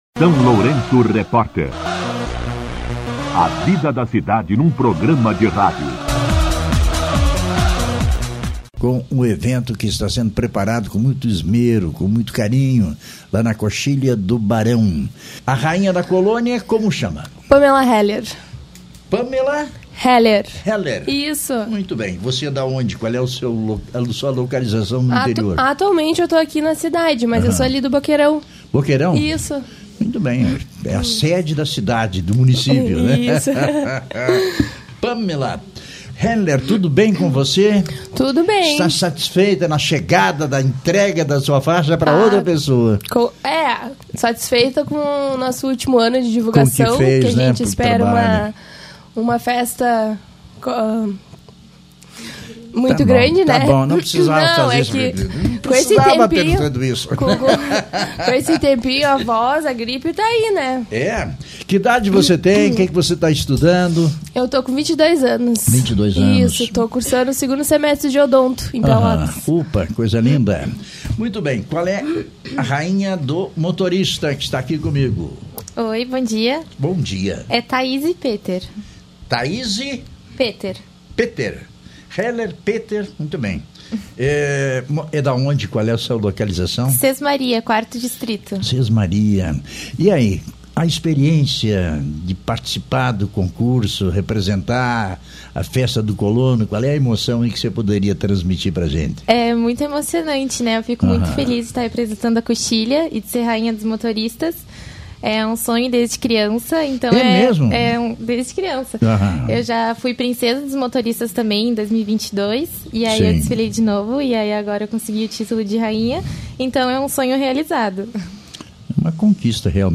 Na manhã desta quinta-feira (24), o SLR RÁDIO recebeu a visita especial das Soberanas da Coxilha, que vieram divulgar a tradicional Festa do Colono e Motorista:
Durante a entrevista, a corte reforçou o convite para a comunidade prestigiar o evento, que acontece neste domingo, dia 27 de julho, na Coxilha do Barão. A festa celebra e homenageia os trabalhadores do campo e das estradas, com programação especial ao longo do dia.